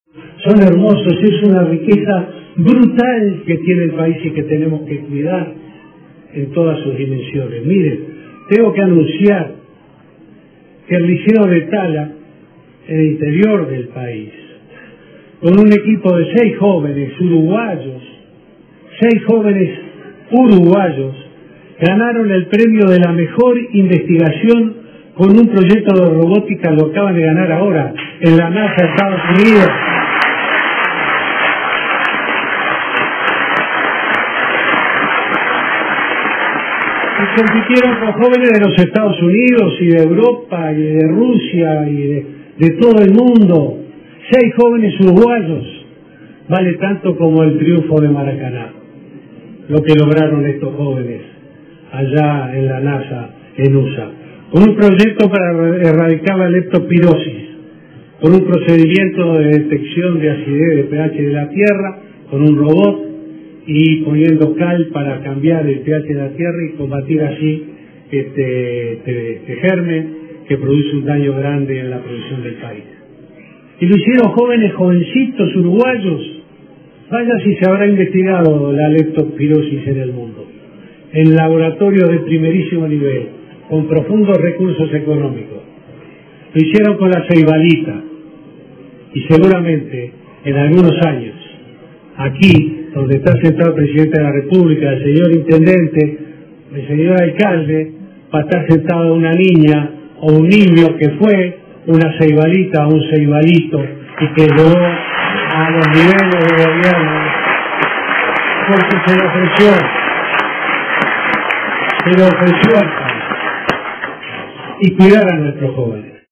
Un grupo de seis estudiantes del liceo de Tala, Canelones, ganaron el premio a la mejor investigación en la Nasa, en Estados Unidos, por un proyecto en robótica para erradicar la leptospirosis. Así lo anunció el presidente Tabaré Vázquez en el Consejo de Ministros abierto en Cerro Largo.